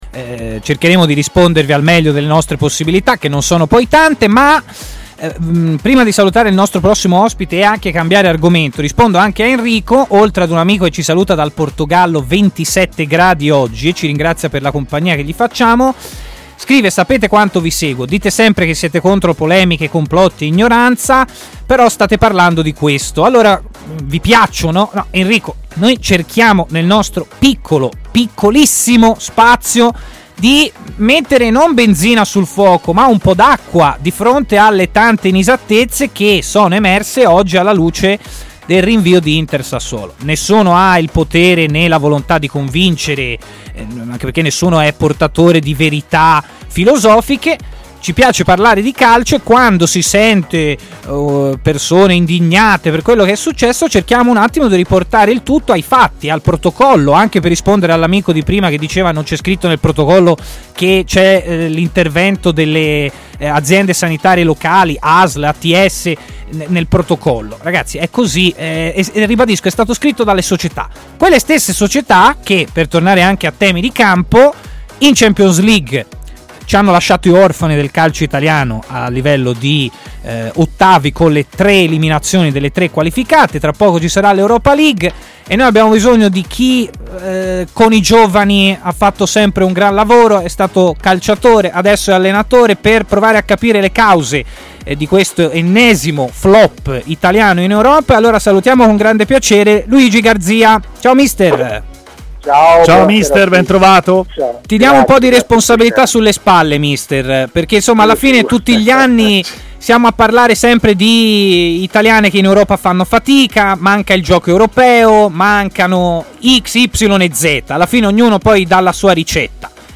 ha parlato a Stadio Aperto, trasmissione di TMW Radio